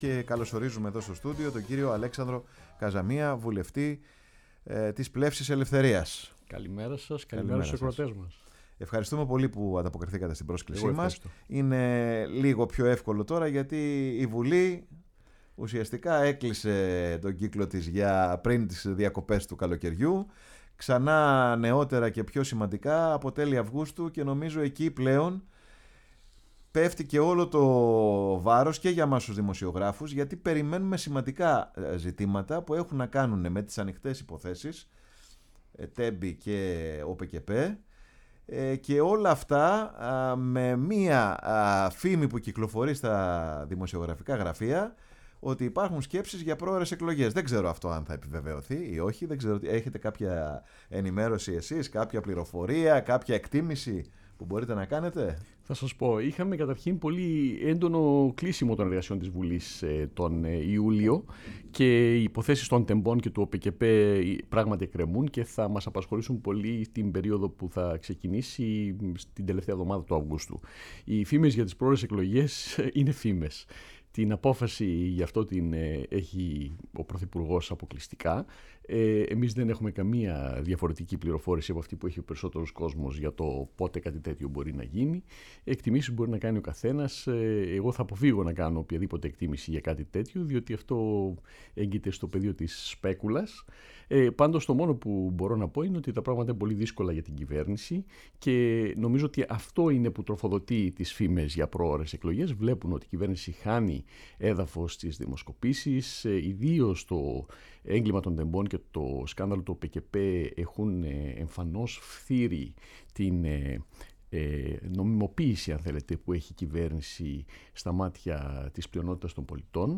Στην εκπομπή “Πάρε τον Χρόνο σου”, φιλοξενήσαμε στο στούντιο της Φωνής της Ελλάδας τον κ. Αλέξανδρο Καζαμία, βουλευτή της Πλεύσης Ελευθερίας, προερχόμενο από την Ομογένεια, καθώς έχει γεννηθεί και μεγαλώσει στην Αίγυπτο ενώ, στη συνέχεια, σπούδασε και εργάστηκε στην Αγγλία.
Συνεντεύξεις